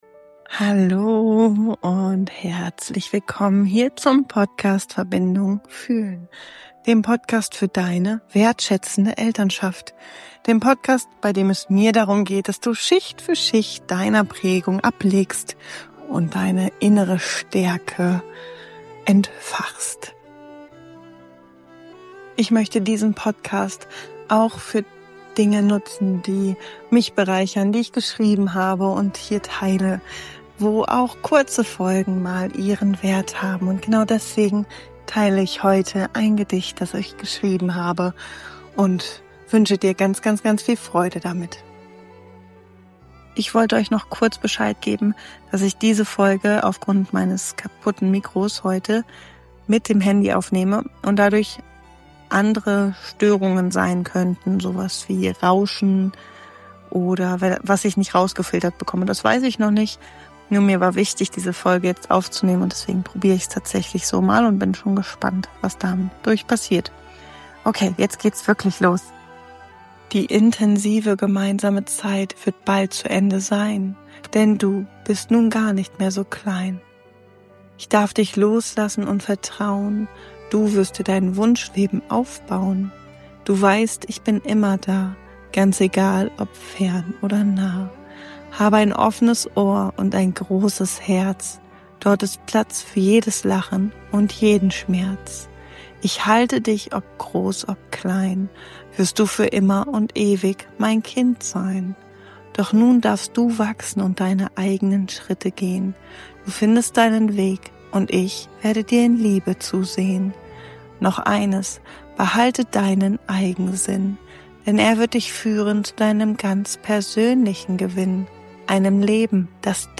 Behalte deinen Eigensinn - Gedicht von mir